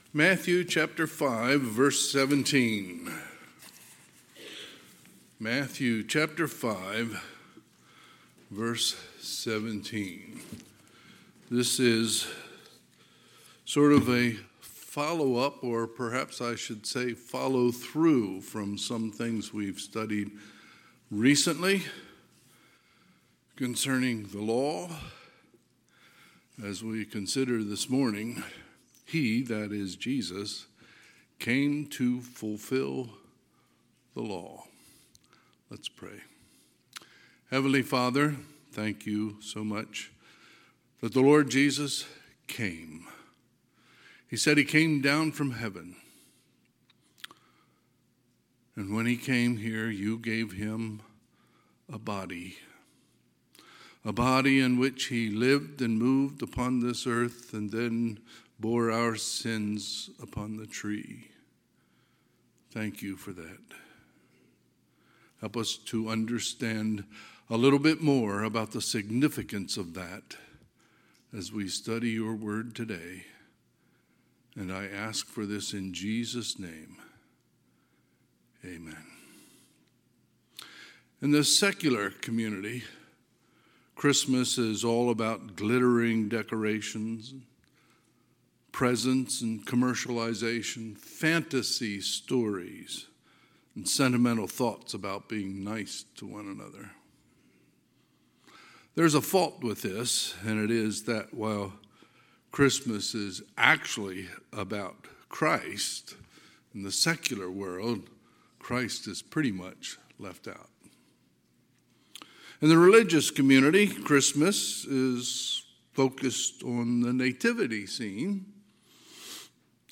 Sunday, December 3, 2023 – Sunday AM
Sermons